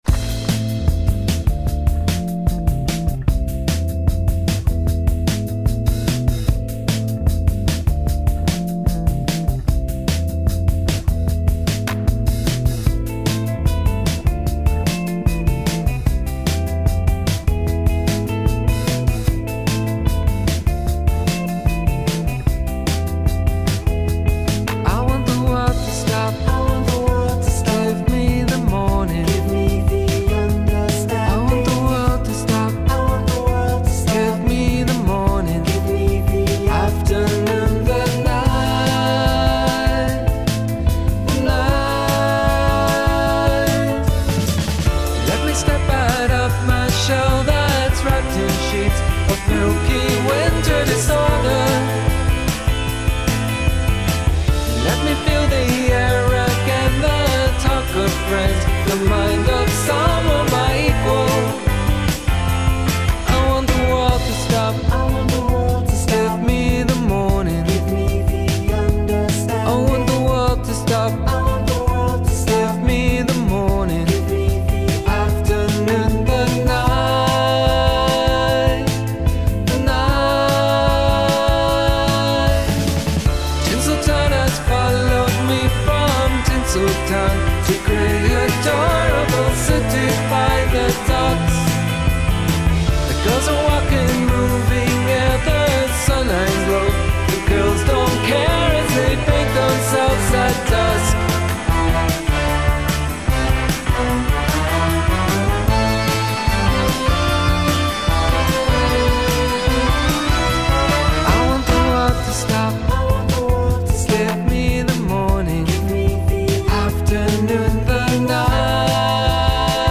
Сегодня в Песне Дня ветераны тви-попа из Глазго